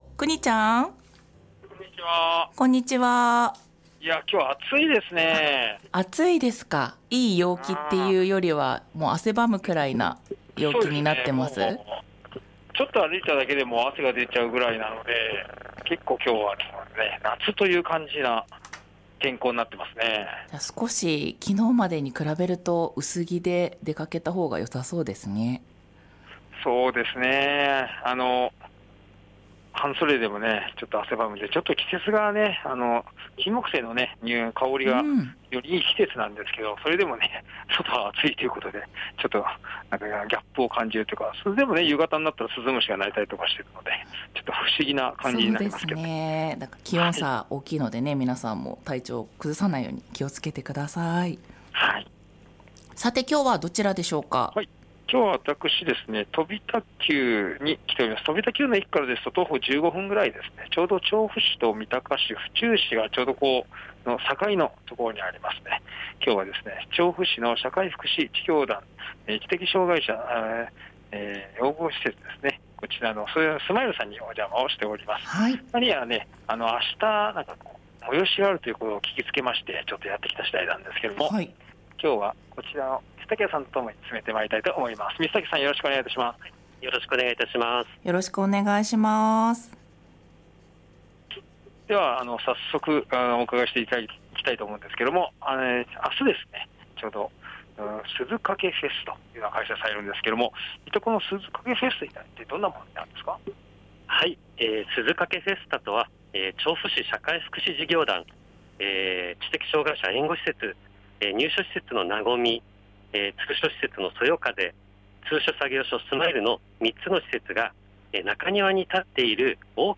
午後のカフェテラス 街角レポート
一番秋が好きな季節なのに・・・ さて今週は西町に出没です。